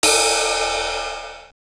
LM-2_RIDE_1_TL.wav